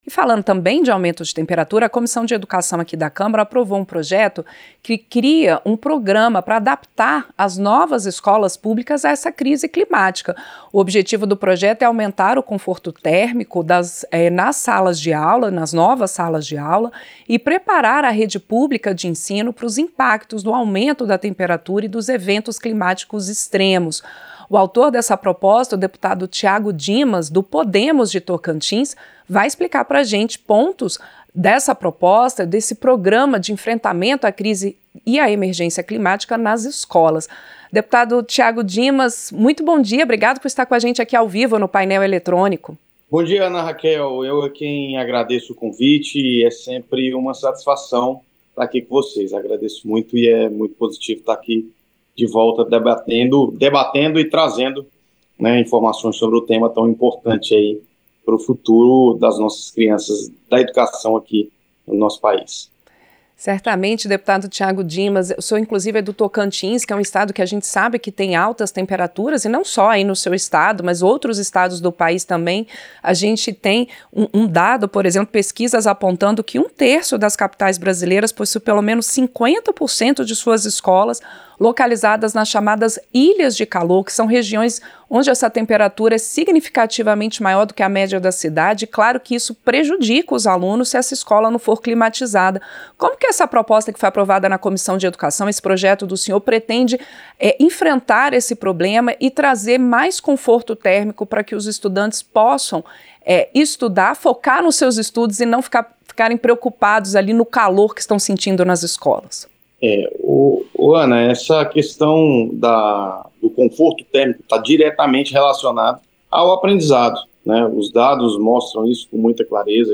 Entrevista - Dep. Tiago Dimas (Podemos-TO)